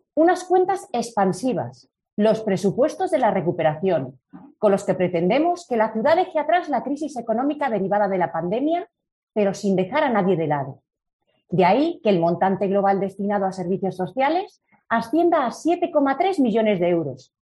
Ángela García Almeida, teniente de alcalde de Hacienda. Pleno presupuestos 2022